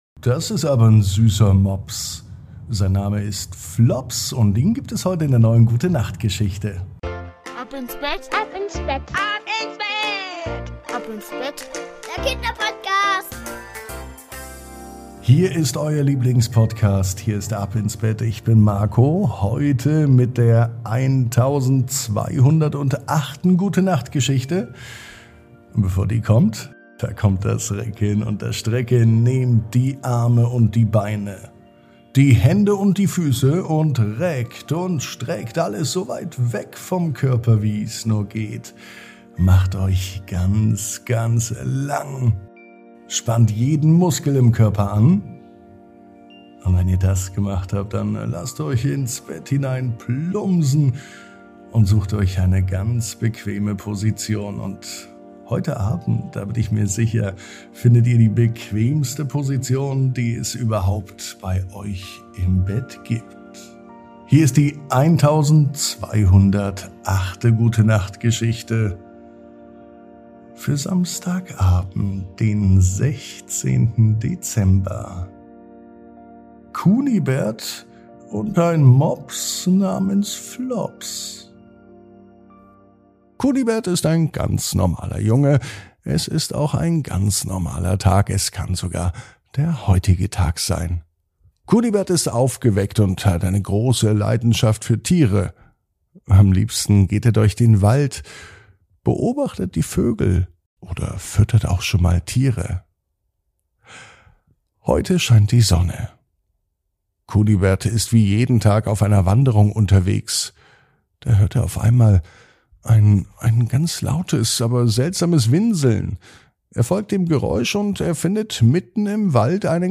Die Gute Nacht Geschichte für Samstag